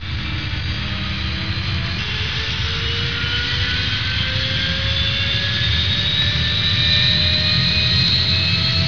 دانلود آهنگ طیاره 47 از افکت صوتی حمل و نقل
دانلود صدای طیاره 47 از ساعد نیوز با لینک مستقیم و کیفیت بالا
جلوه های صوتی